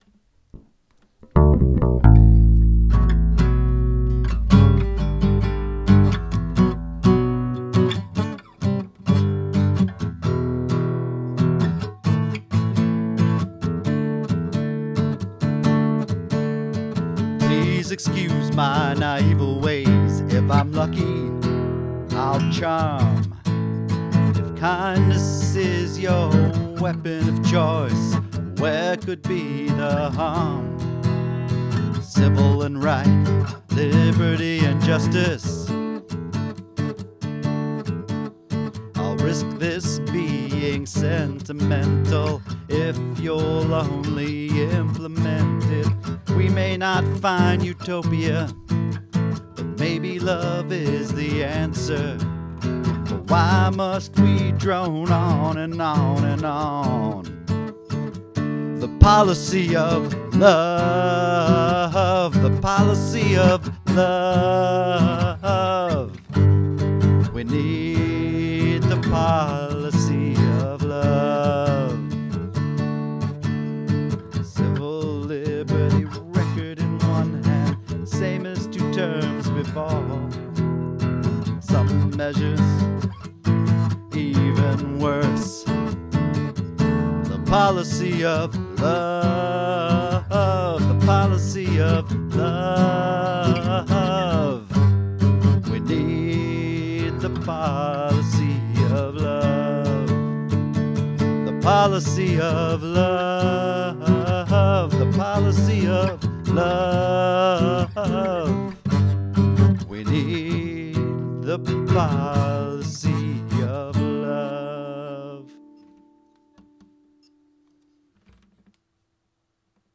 (Note the bass intro – ran out of time to continue working on the bass part this morning – darn day job.):